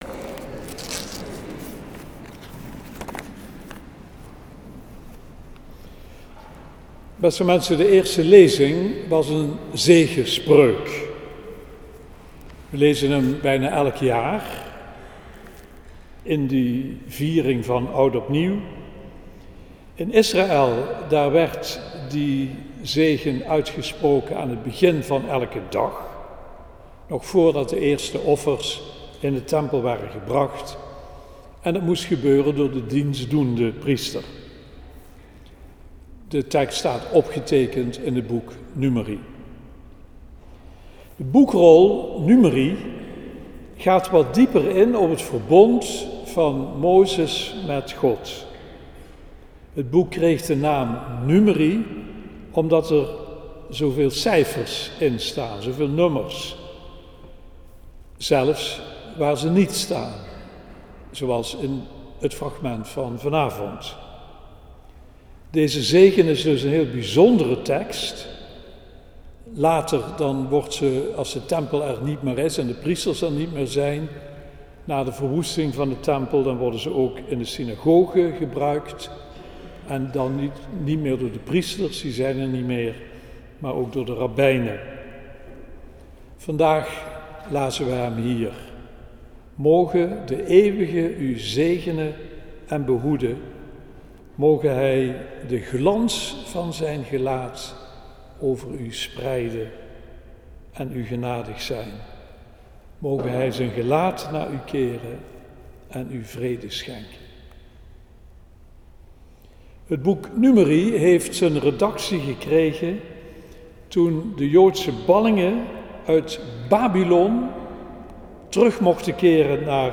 preek 1 jan 2020 - nieuwjaar.mp3